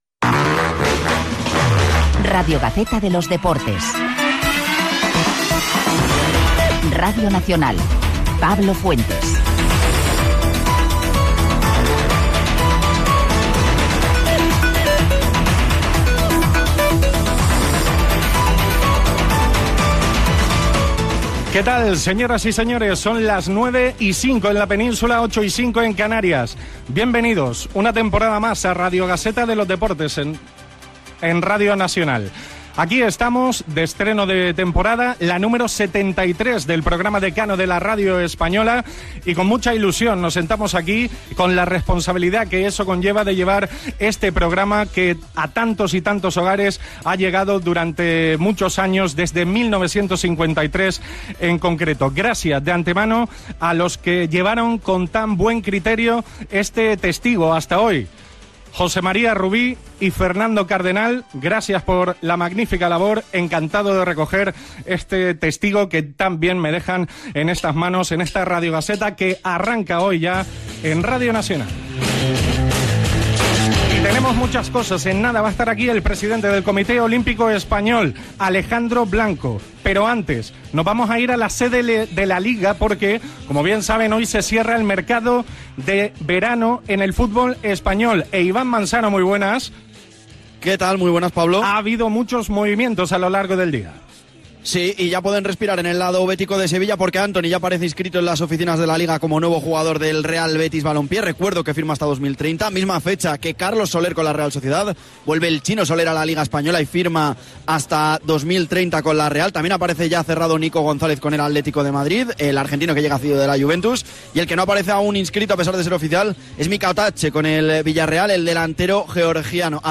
Careta, presentació del programa per part del nou equip que el farà.
Esportiu